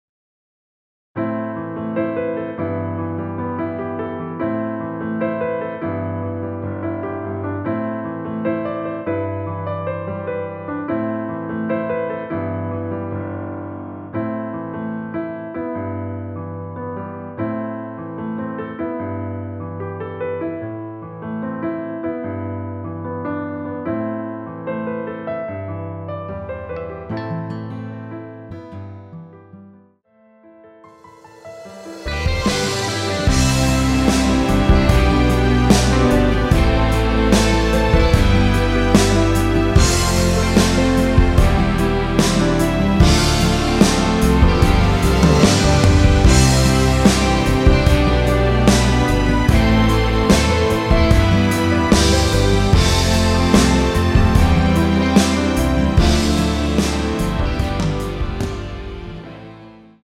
Am
앞부분30초, 뒷부분30초씩 편집해서 올려 드리고 있습니다.
중간에 음이 끈어지고 다시 나오는 이유는